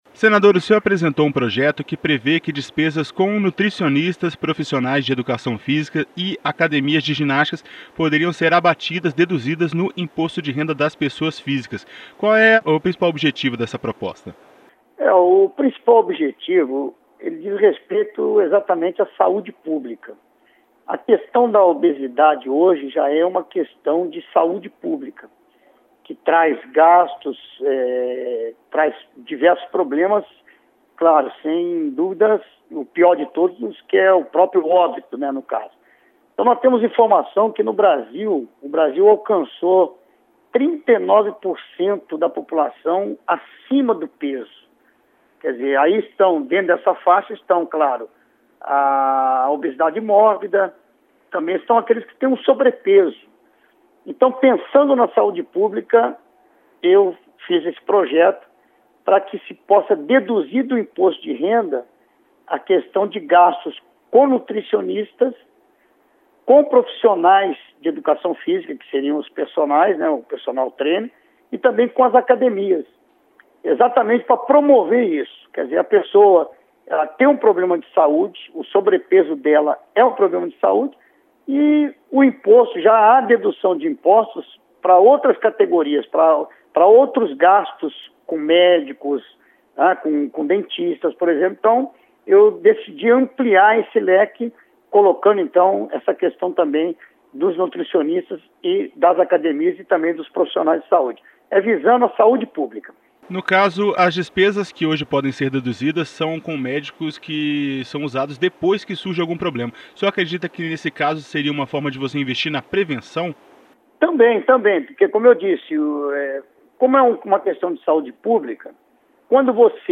Projeto deduz do IR despesas com academia e nutricionistas Entrevista com o senador Eduardo Lopes (PRB-RJ).